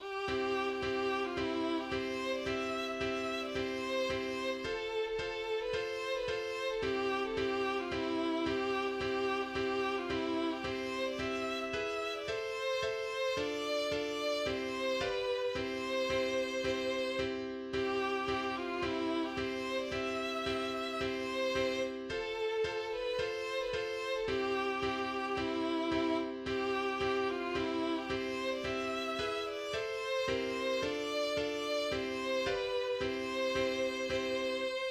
\header{ dedication = "🏰005" title = "Ruhm und Ehre für Garetien" subtitle = "Marschlied" subsubtitle = "John Brown's Body / Battle Hymn of the Republic" meter = "Ingo B." arranger = "William Steffe" } myMusic = { << \chords { \germanChords \set chordChanges=##t s4 c2 c2 c2 c2 c2 c2 c2 c2 f2 f2 f2 f2 c2 c2 c2 c2 c2 c2 c2 c2 c2 e2 a2:m a2:m d2:m d2:m c2 g2:7 c2 c2 c2 c2 c2 c2 c2 c2 c2 c2 c2 c2 f2 f2 f2 f2 c2 c2 c2 c2 c2 c2 c2 c2 c2 e2 a2:m c2:7 d2:m d2:m c2 g2:7 c2 c2 c2 } \relative c'' { \time 4/4 \set Staff.midiInstrument="violin" \key c \major \tempo 4=220 \partial 4 g4 | g4. g8 g4. f8 | e4. g8 c4. d8 | e4. e8 e4. d8 | c2 c4. c8 | a4. a8 a4. b8 | c4. b8 c4. a8 | g4. a8 g4. f8 | e2 g4. g8 | g4. g8 g4. f8 | e4. g8 c4. d8 | e4. e8 e4. d8 | c2 c2 | d2 d2 | c2 b2 | c1~ | c2 r2 | g2. f4 | e4. g8 c4. d8 | e1 | c2. r4 | a2. b4 | c4. b8 c4. a8 | g1 | e2. r4 | g2. f4 | e4. g8 c4. d8 | e2. d4 | c2 c2 | d2 d2 | c2 b2 | c1~ | c2.